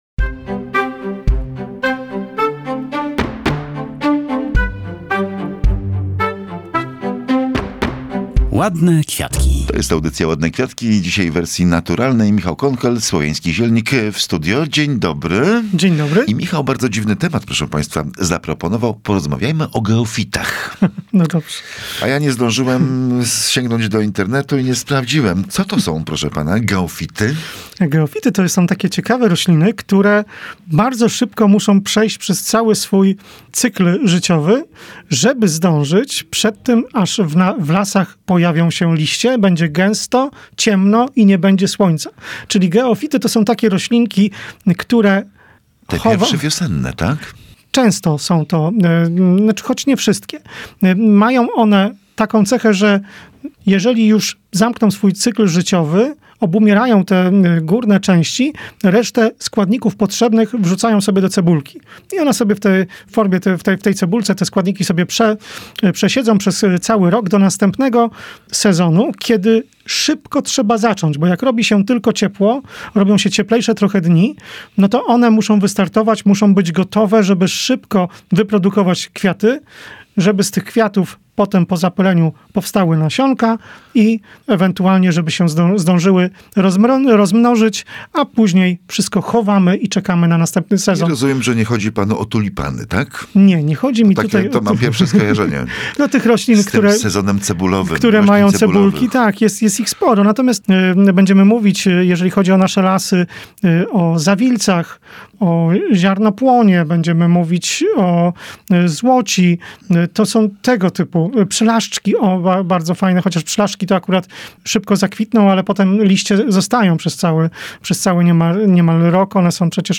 Do czego spieszy się przebiśniegom? Rozmowa o geofitach, wiosennych sprinterach